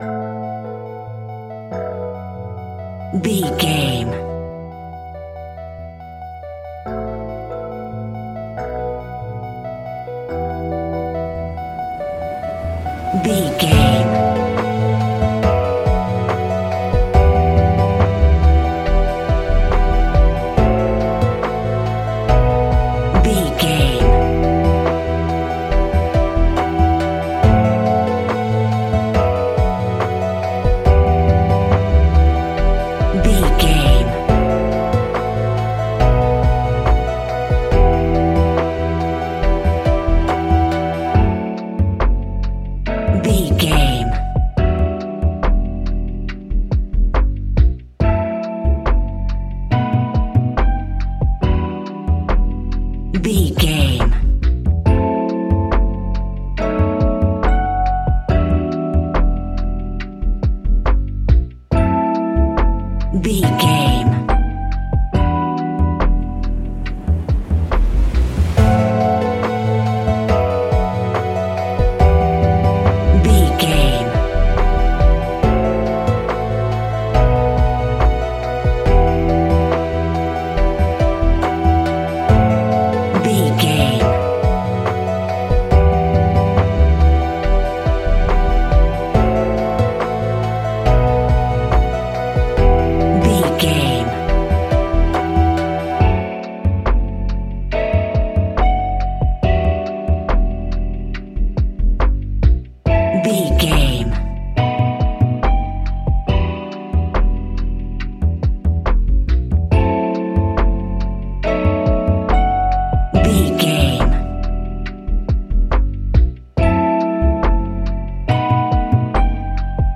Ionian/Major
E♭
laid back
Lounge
sparse
new age
chilled electronica
ambient
atmospheric
morphing
instrumentals